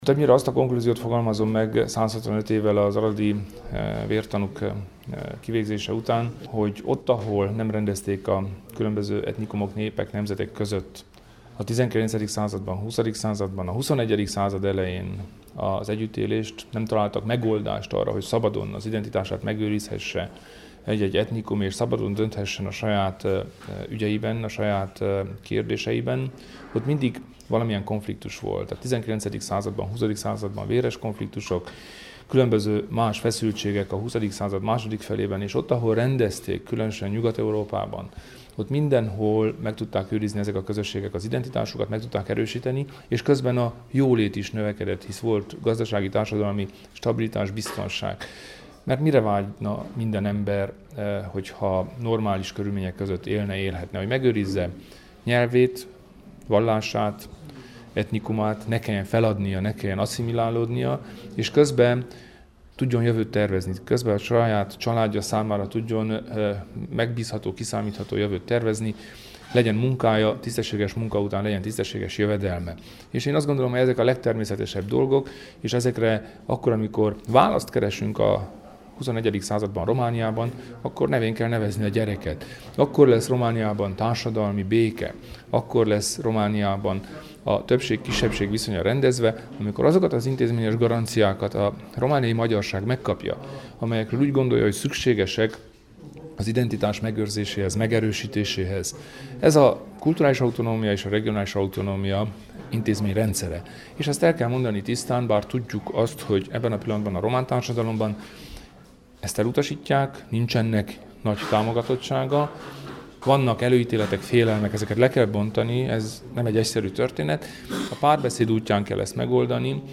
Államfőjelölti programjának ismertetésével kezdte mondandóját Kelemen Hunor, a Romániai Magyar Demokrata Szövetség (RMDSZ) elnöke, román miniszterelnök-helyettes, kulturális miniszter Aradon, az október 6-i megemlékezések nyitányaként tartott sajtótájékoztatón.